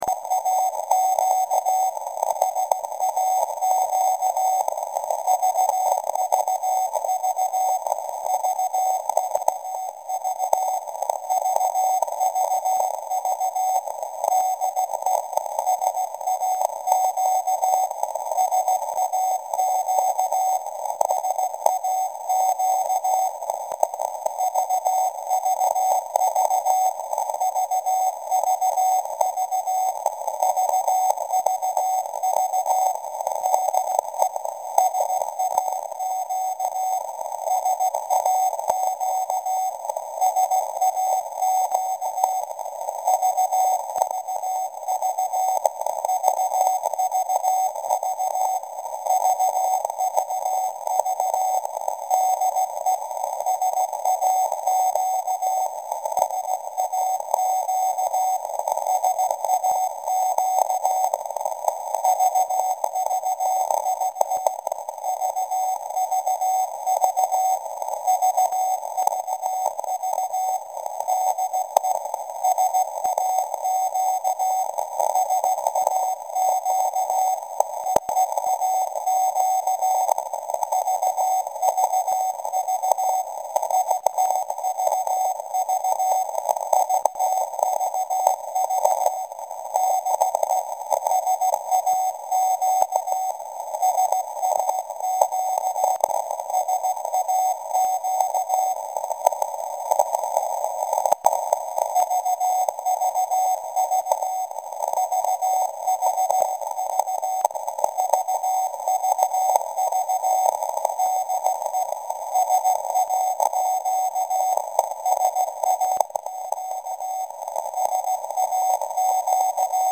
2 Juli 25: Mitschnitt von SAQ 17.2 khz | BV-Nordrhein VFDB e. V.